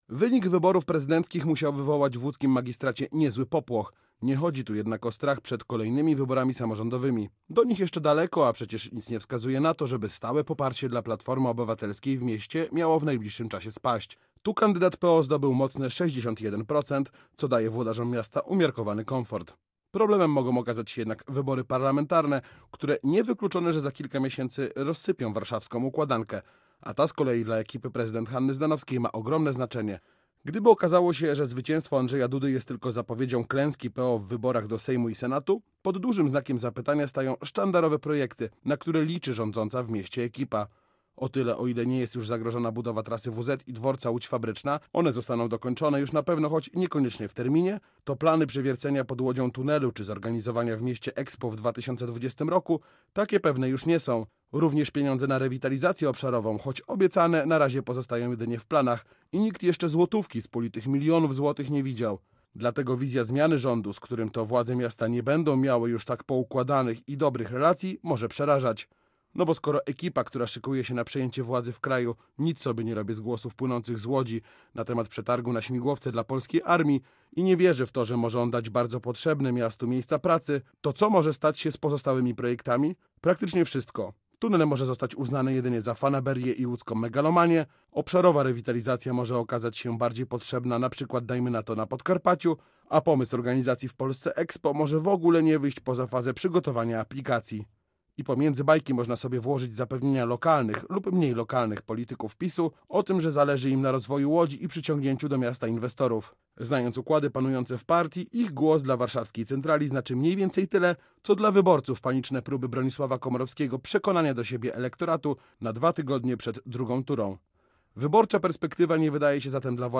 Popłoch (felieton z 29.05.2015) - Radio Łódź